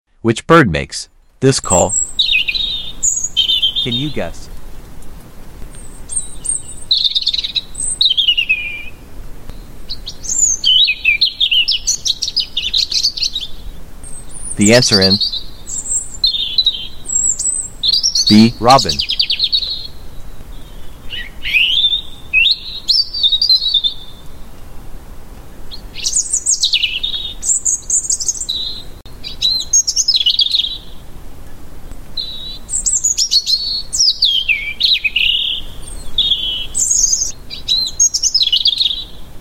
Which bird makes this call..?..can sound effects free download